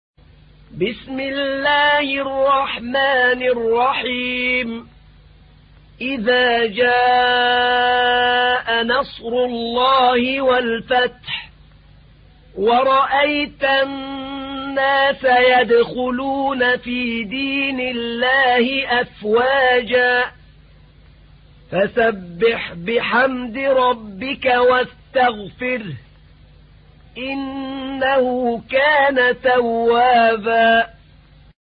تحميل : 110. سورة النصر / القارئ أحمد نعينع / القرآن الكريم / موقع يا حسين